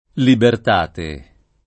libertate [ libert # te ]